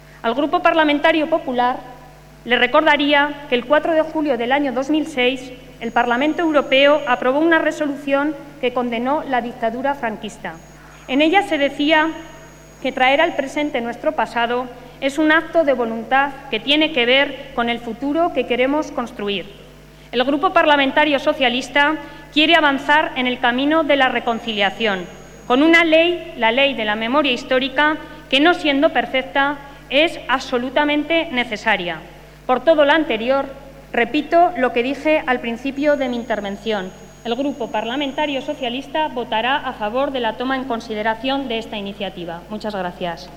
Susana Sumelzo interviene en el debate para declarar el 18 de julio día oficial de condena de la dictadura.